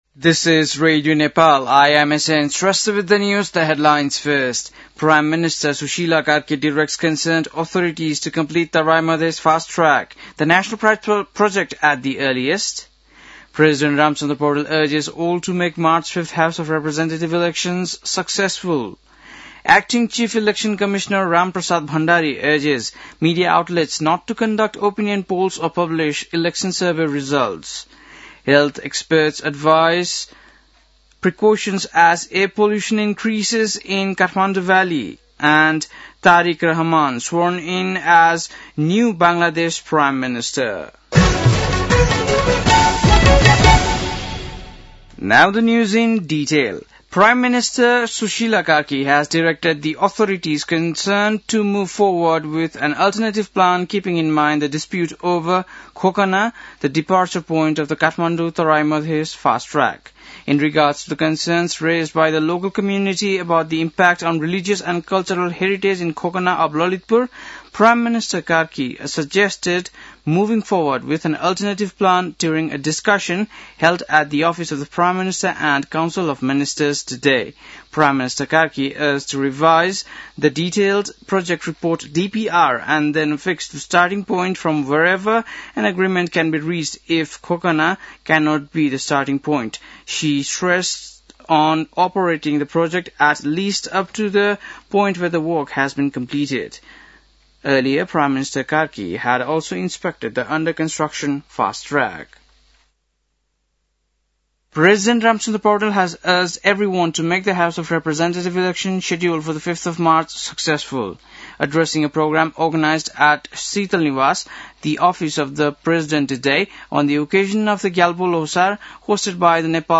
बेलुकी ८ बजेको अङ्ग्रेजी समाचार : ५ फागुन , २०८२
8-pm-english-news-11-5.mp3